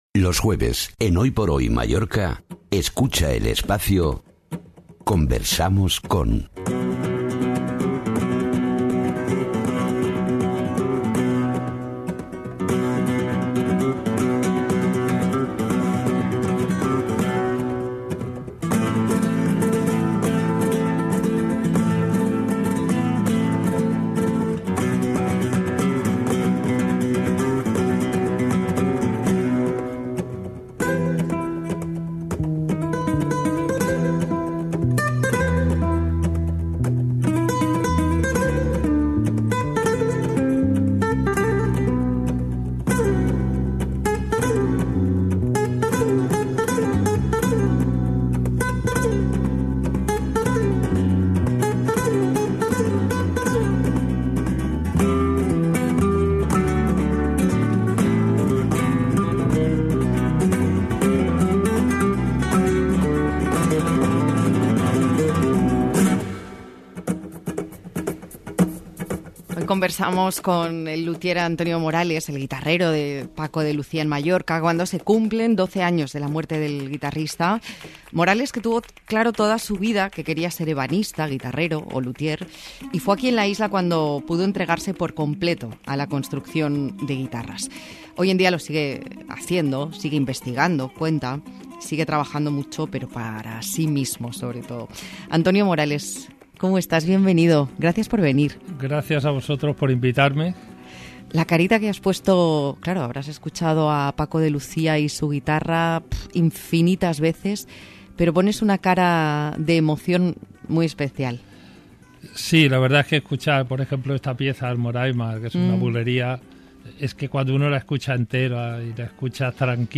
Entrevista Hoy por Hoy en la cadena SER 26/2/2026
Audio de la entrevista en el programa Hoy por Hoy en la Cadena SER 26/2/2026.